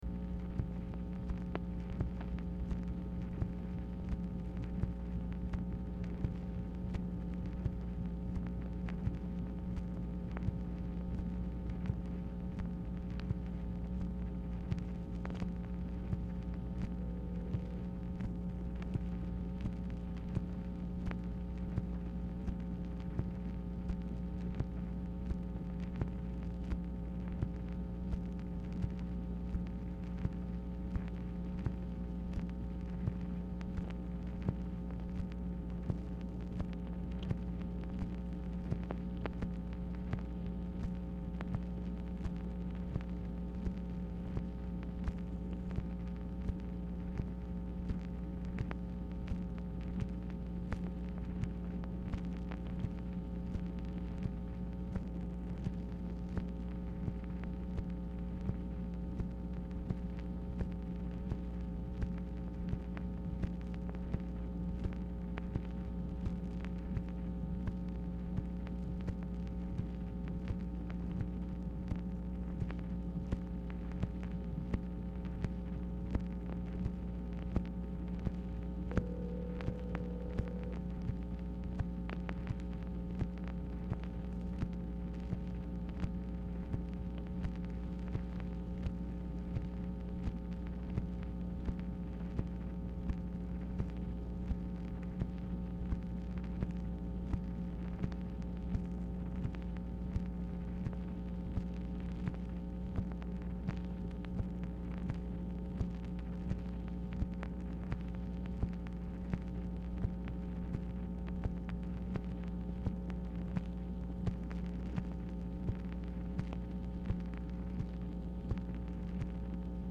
Telephone conversation
MACHINE NOISE
Dictation belt